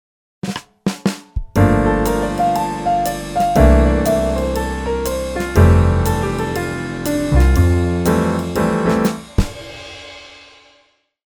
Jazz Piano – 2-5-1 Lick 1
Here is a lick in the key of F to get you started or enhance your repertoire of 2-5-1 licks.
Even though the lick consists only of the notes of the F major scale, it sounds quite good if you add bass and drums:
It starts with an A as an approach to an arpeggio of the G minor 9 chord, with the first notes played in a triplet.
The notes in bar 2 are mostly taken from the C13 chord including the root C.
The last section is moving around until it gets to rest on the root F. The ninth, G, is also part of the F major 9 chord and sounds very nice in this context.
jazz-piano-2-5-1-lick.mp3